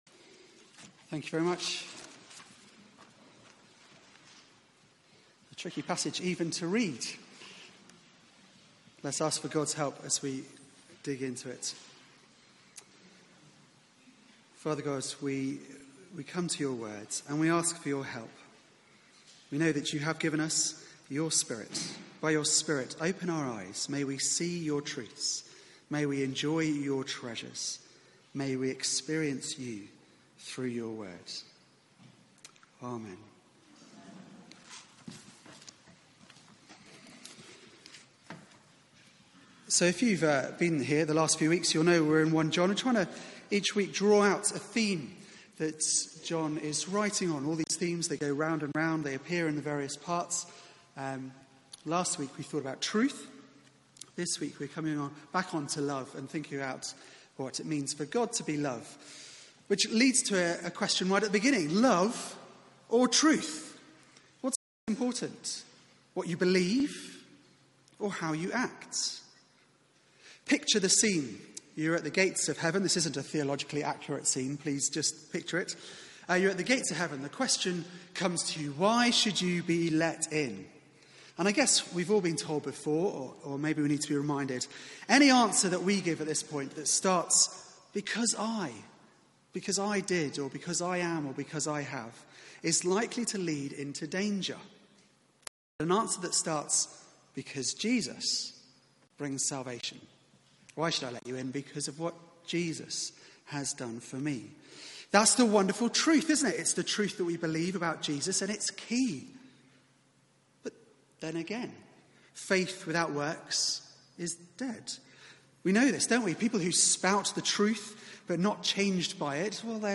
Media for 4pm Service on Sun 13th May 2018 16:00 Speaker:
Sermon